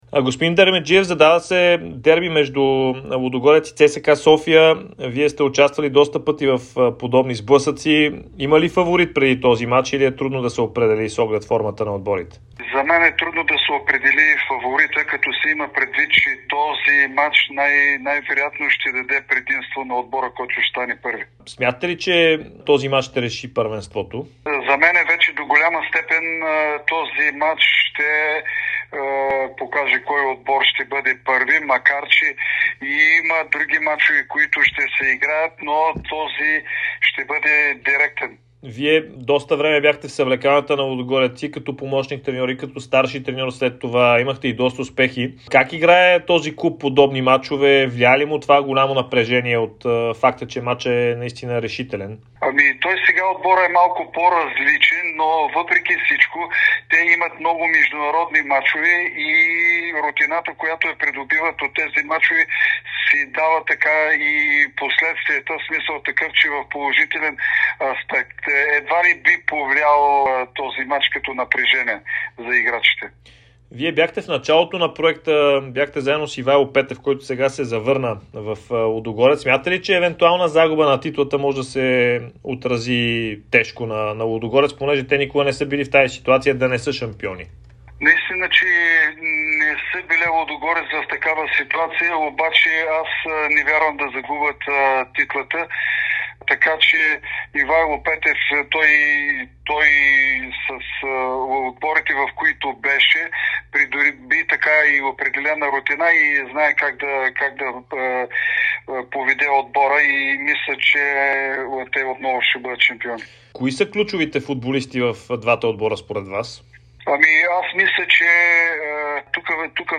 Бившият треньор на Лудогорец, Левски и националния отбор на България Георги Дерменджиев даде специално интервю за Дарик радио и dsport, в което коментира предстоящата битка между „орлите“ и ЦСКА, представянето на Елин Топузаков при „сините“, неговото бъдеще и стадионите в Пловдив.